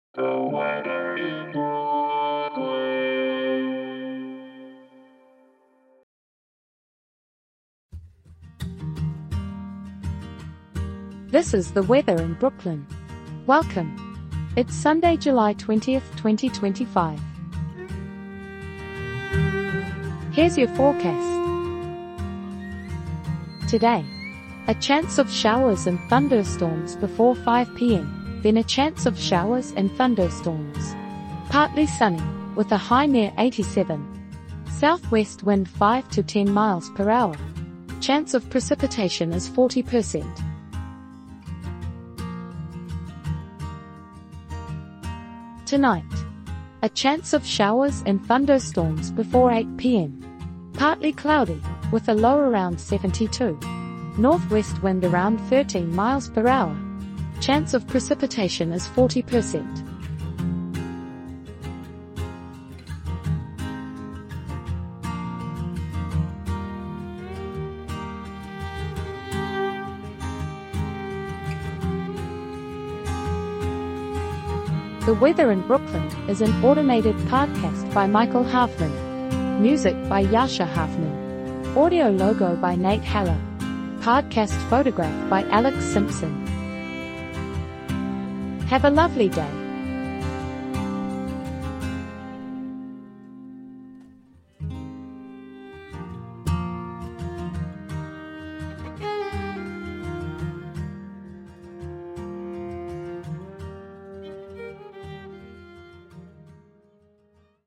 is generated automatically.